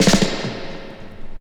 16DR.BREAK.wav